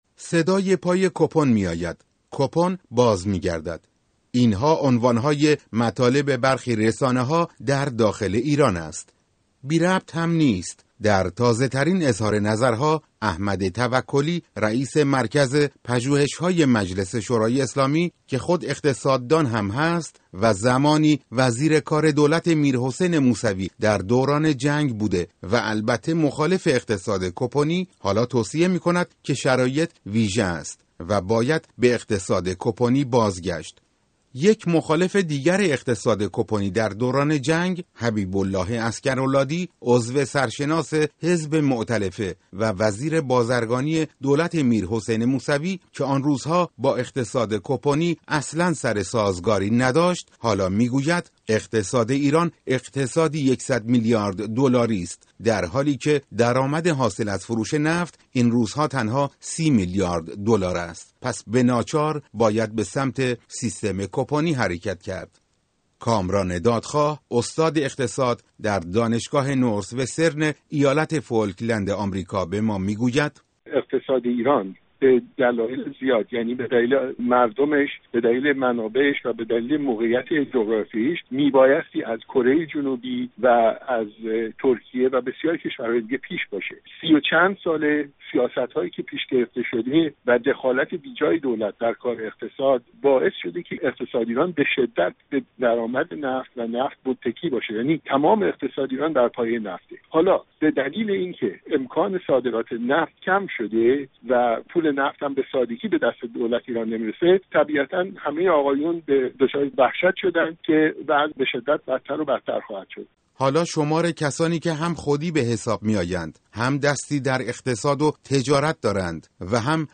گزارش رادیویی درباره تمایل برای بازگشت به سیستم کوپنی در ایران